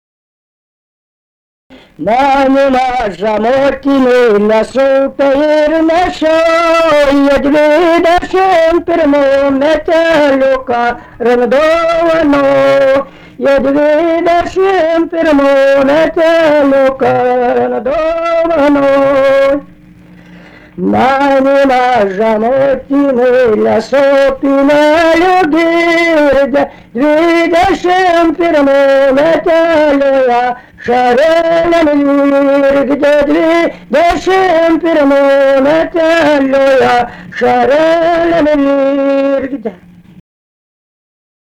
daina, karinė-istorinė
Venslaviškiai
vokalinis